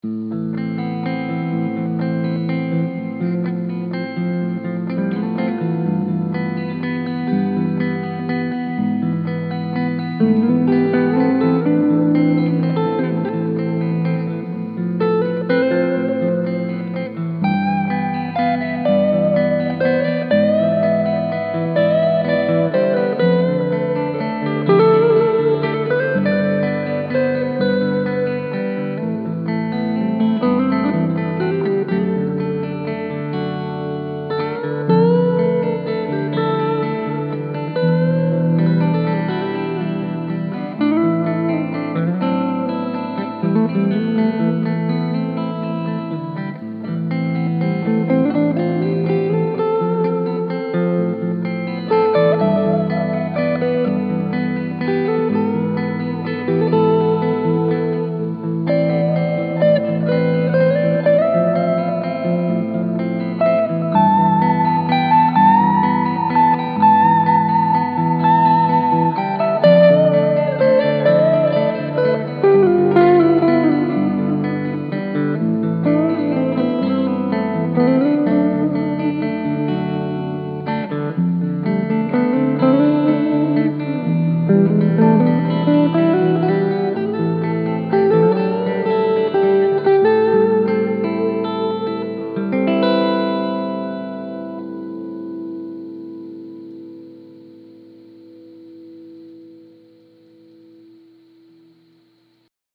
The clips below were all recorded using my 1958 Fender Champ output to a Jensen Jet Falcon 1 X 12. I start out each clip with a dry signal, then play it again with some “grease.”
Left Channel: Les Paul Middle Pickup, Reverb and Mix same settings as above
Right Channel: Les Paul Neck Pickup, Reverb: Dimed, Mix: 8pm
Turned up all the way, you get this cavernous room sound, but with the Mix set real low, it becomes a much more subtle effect, providing almost a delay-like ambience without the echos.